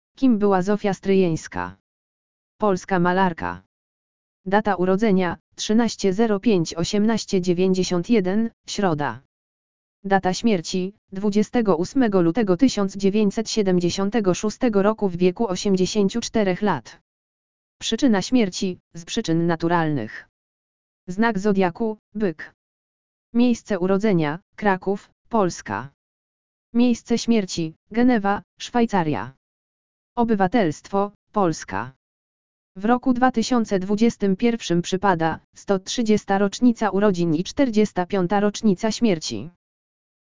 audio_lektor_urodziny_zofii_stryjenskiej.mp3